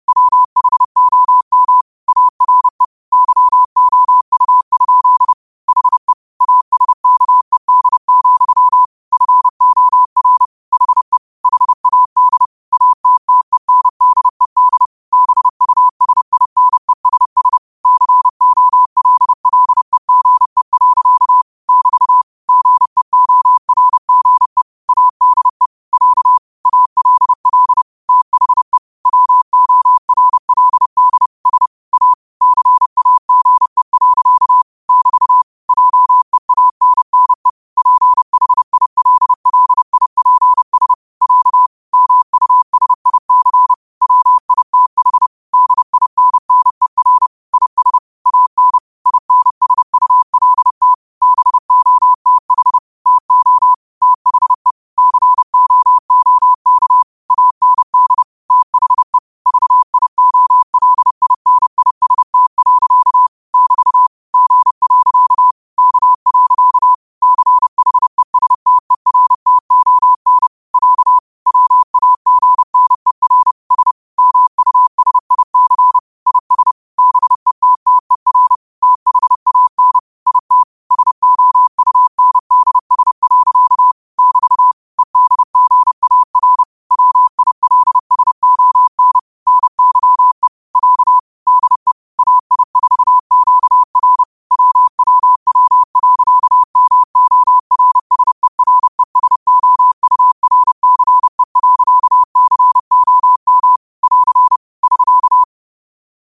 30 WPM morse code quotes for Sat, 16 Aug 2025 by QOTD at 30 WPM
Quotes for Sat, 16 Aug 2025 in Morse Code at 30 words per minute.